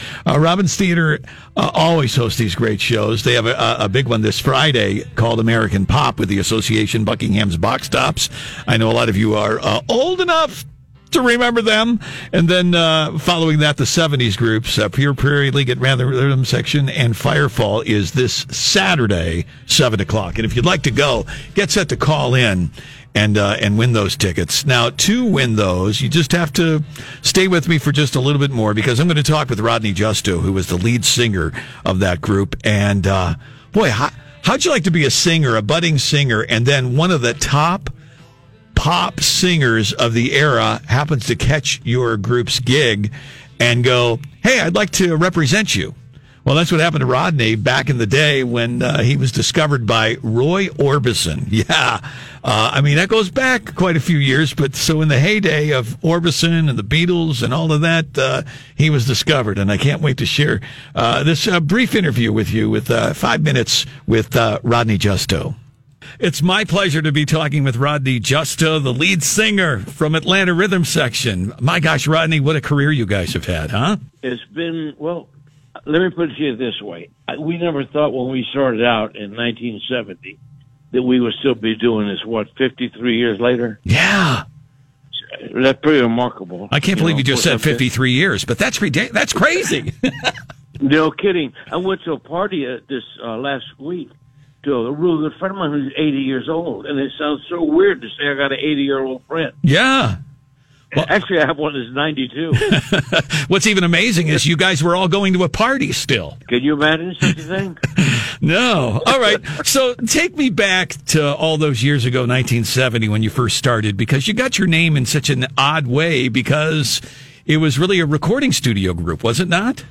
Fun interview with a rock legend.